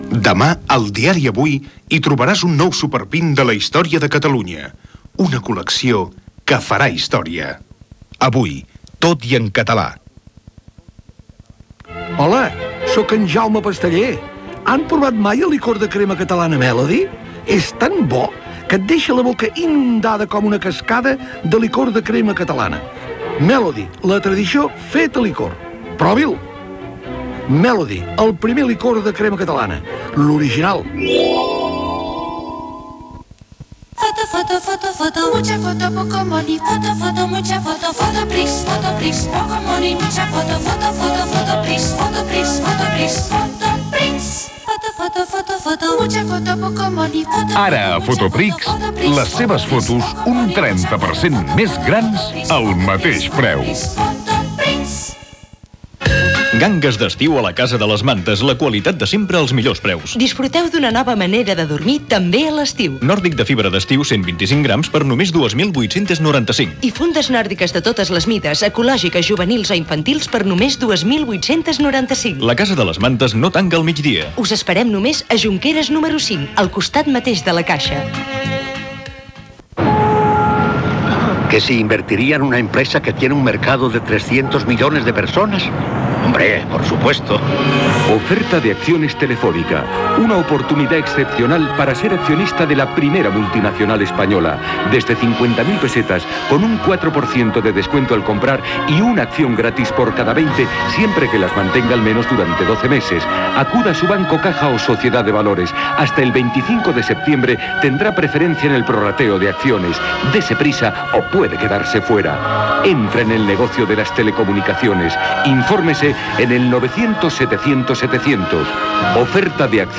Bloc publicitari.